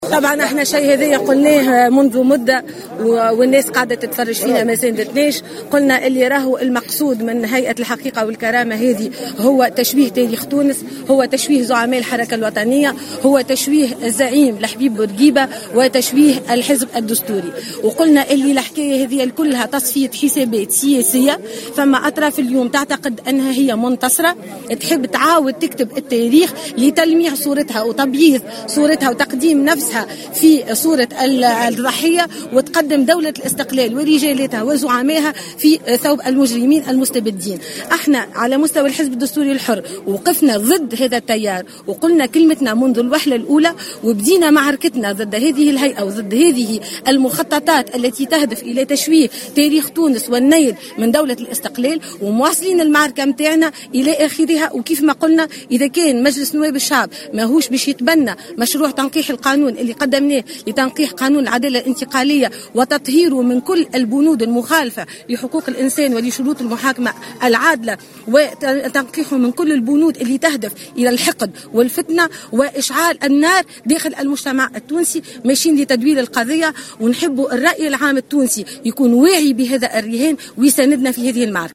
وقالت عبير موسي، التي تؤدي اليوم الخميس، زيارة إلى المنستير بمناسبة الذكرى السابعة عشرة لرحيل الزعيم بورقيبة، في تصريح لمراسل الجوهرة أف أم، أن الحملة تندرج في إطار تصفية حسابات سياسية تقوم بها "أطراف" تحاول كتابة التاريخ لتقديم صورة جديدة لها تبرزها في ثوب الضحية، وصورة أخرى تبرز رجال الإستقلال كمجرمين، على حد تعبيرها.